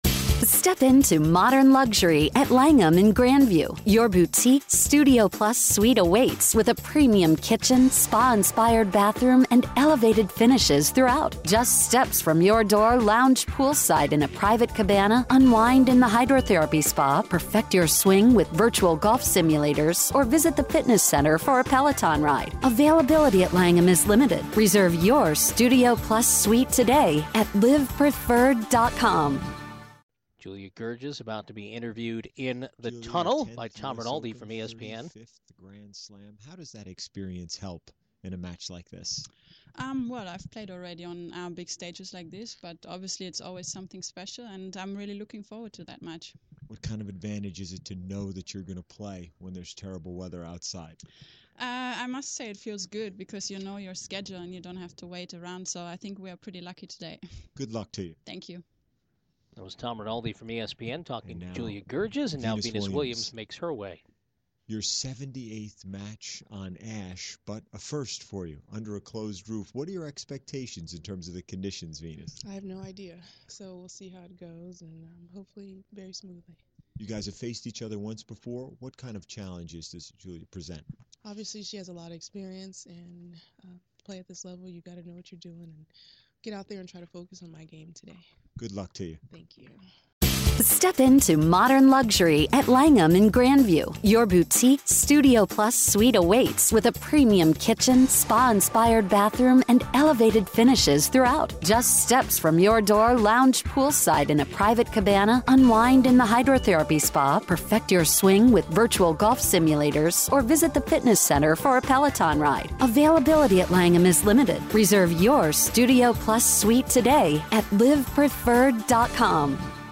Venus, Goerges Pre-Match Interview